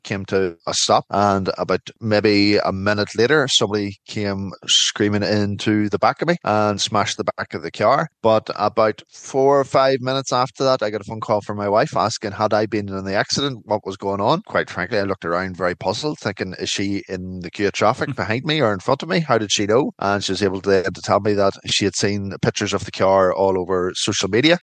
Cllr Quinn says his own experience shows the speed at which pictures and videos can spread ……………..